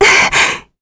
Laias-Vox_Damage_kr_01.wav